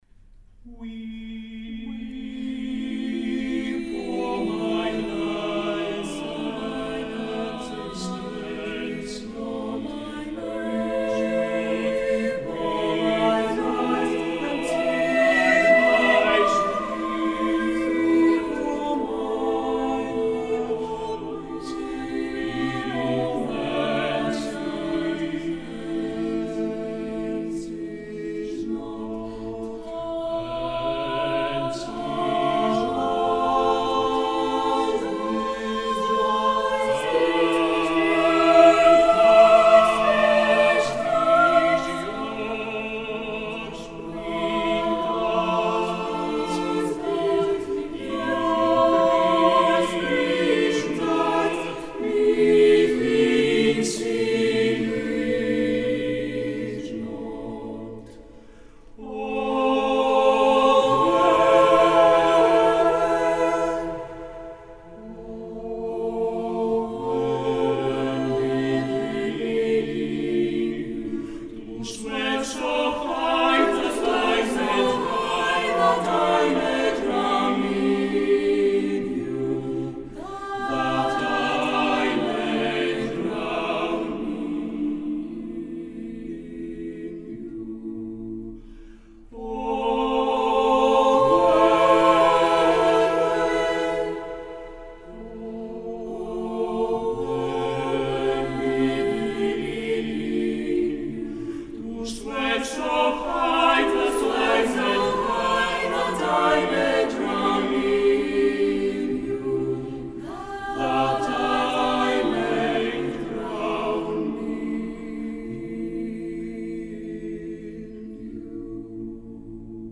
Με ισοστάθμιση, αντήχηση και στερεοφωνία